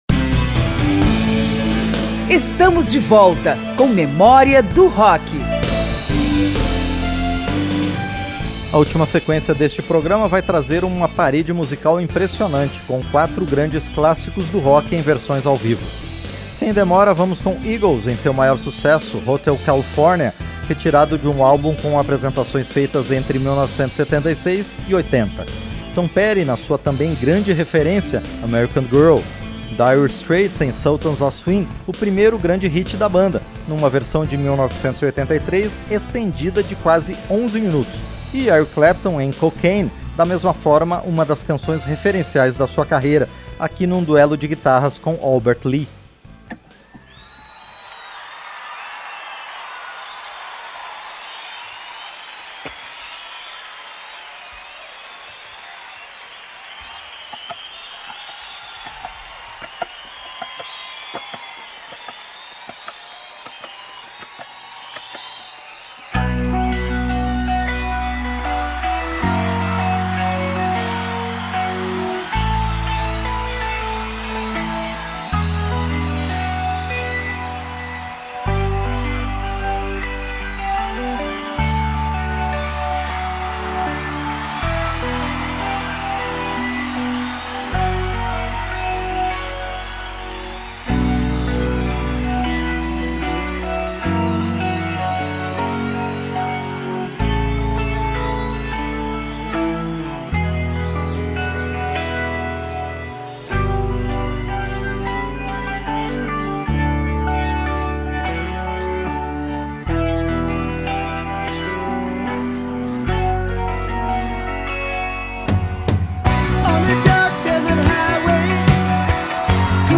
apresentações ao vivo